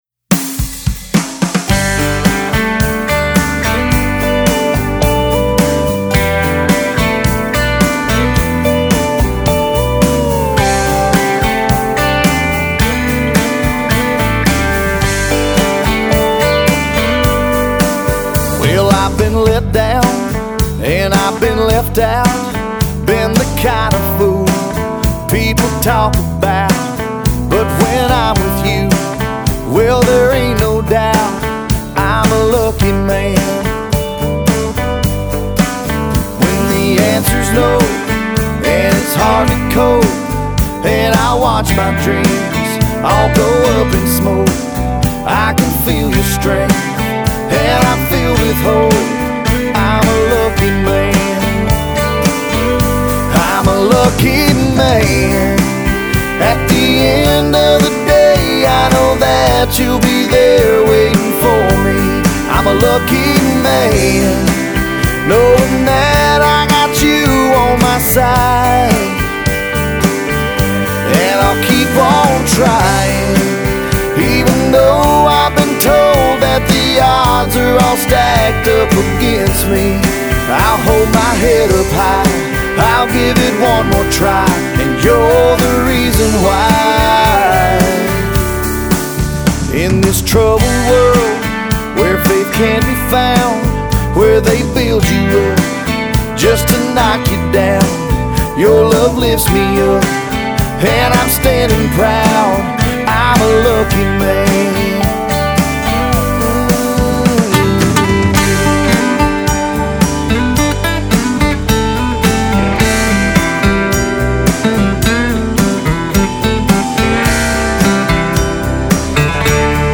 MASTER RECORDINGS - Country